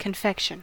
Ääntäminen
Synonyymit lolly sweet Ääntäminen US Tuntematon aksentti: IPA : /kənˈfɛkʃən/ Haettu sana löytyi näillä lähdekielillä: englanti Käännös Substantiivit 1.